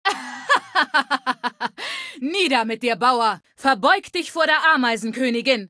Datei:Femaleadult01default ms02 greeting 0003c8d8.ogg
Fallout 3: Audiodialoge